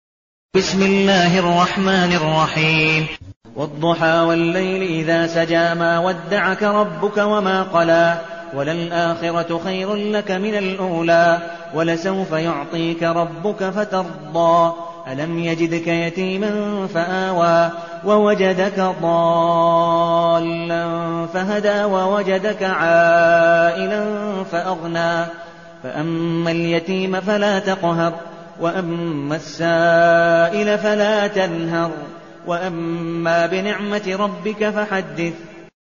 المكان: المسجد النبوي الشيخ: عبدالودود بن مقبول حنيف عبدالودود بن مقبول حنيف الضحى